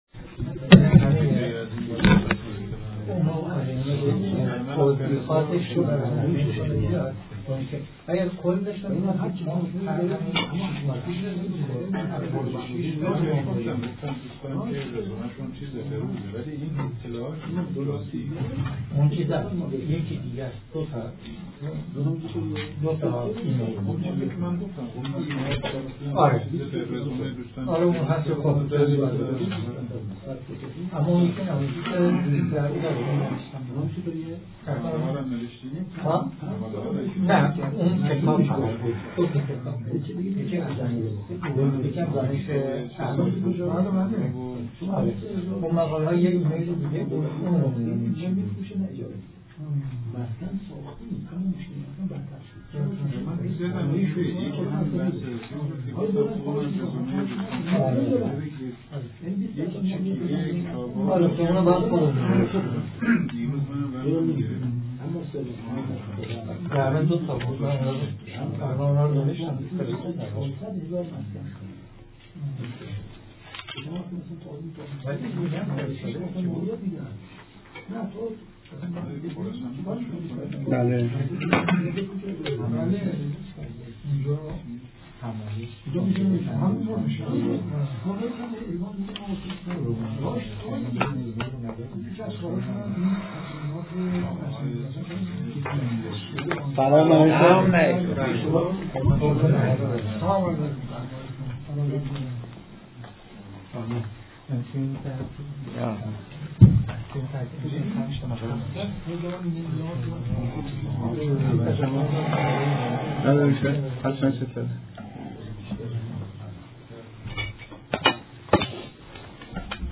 گزارش سخنرانی
چهارمین ویژه برنامه های ایام بزرگداشت علامه طباطبائی